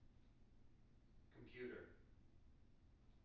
wake-word
tng-computer-305.wav